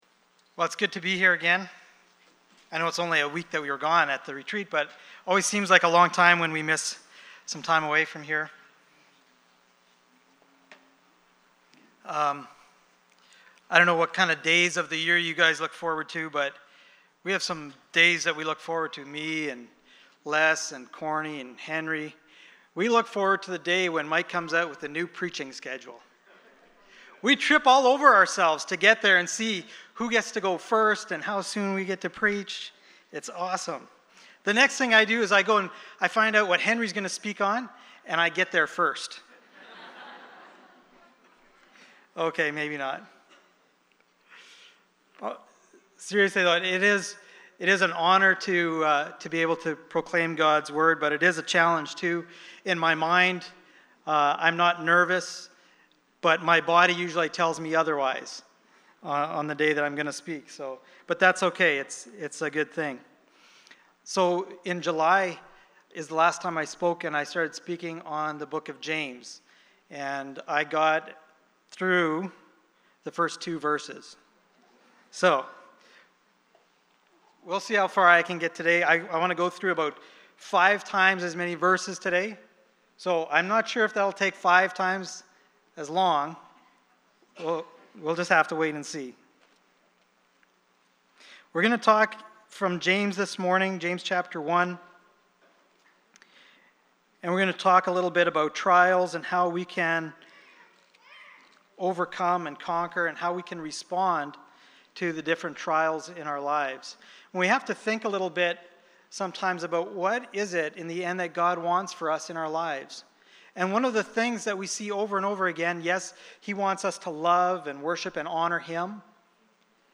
James Passage: James 1:2-11 Service Type: Sunday Morning « Shepherd and Sheep